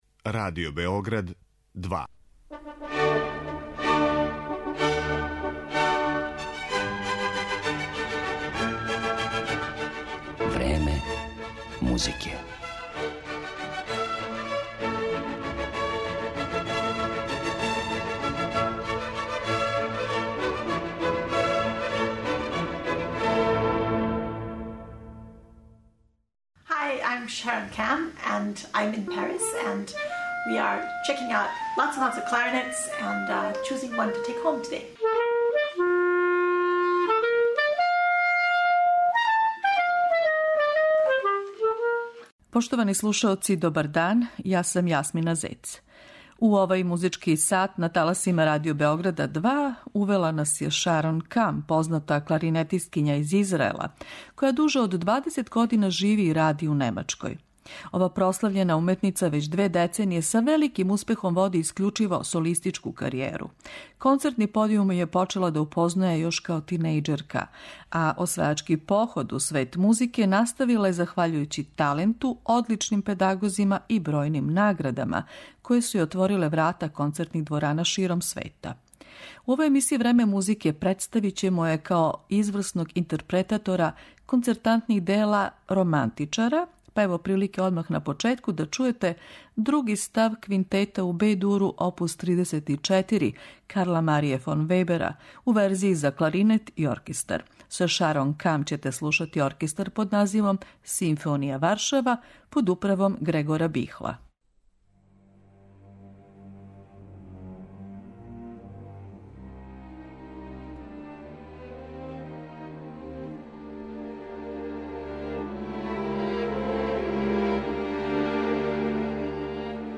Емитоваћемо снимке Шарон Кам, познате кларинетисткиње из Израела, која дуже од две деценије живи и ради у Немачкој.
У данашњој емисији ћемо је представити као изврсну интерпретаторку романтичарског репертоара.